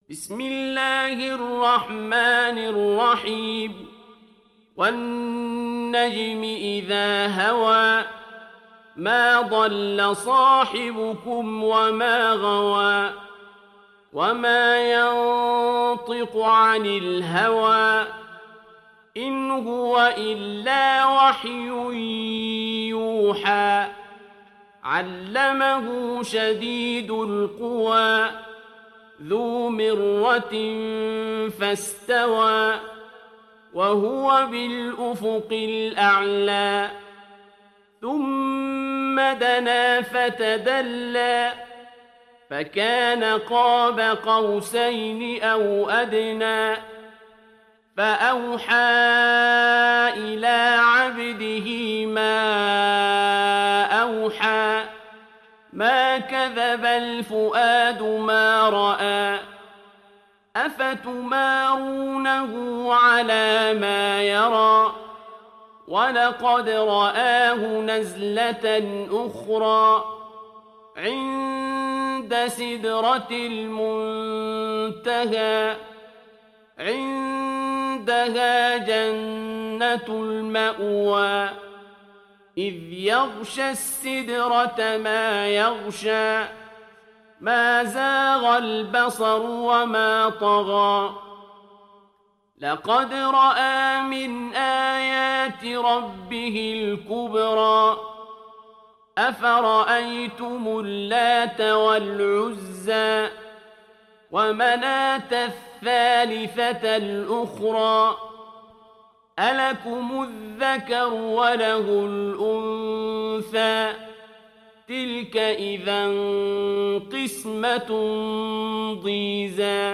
সূরা আন-নাজম ডাউনলোড mp3 Abdul Basit Abd Alsamad উপন্যাস Hafs থেকে Asim, ডাউনলোড করুন এবং কুরআন শুনুন mp3 সম্পূর্ণ সরাসরি লিঙ্ক